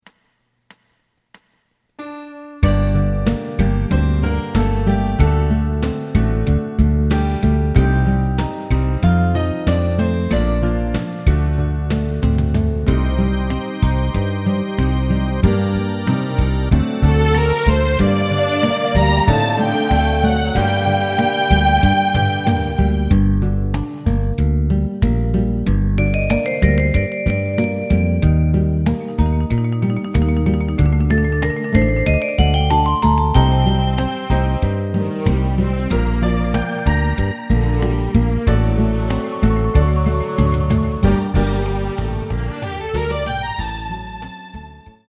エレキバンド用カラオケCD製作・販売
すべての主旋律を１人で演奏するスタイルにアレンジしてみました。
●フルコーラス(カラオケ) 伴奏のみ演奏されます。